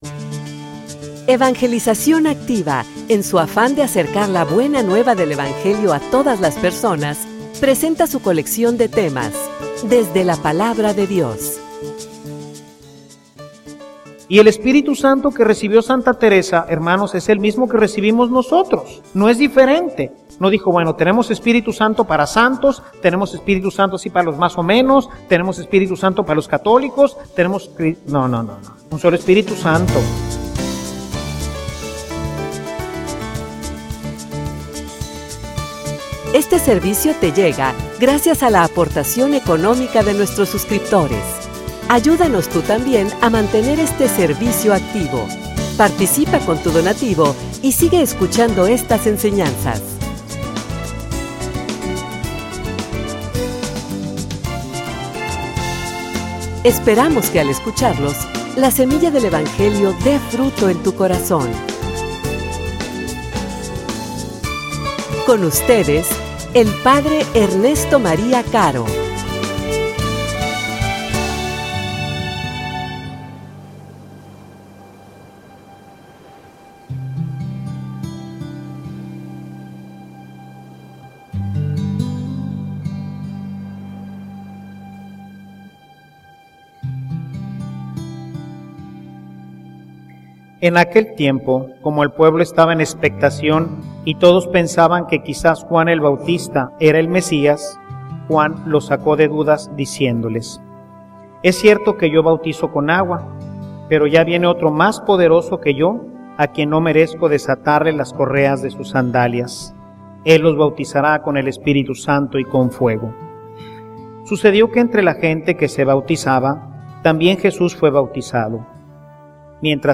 homilia_Las_gracias_del_Bautismo.mp3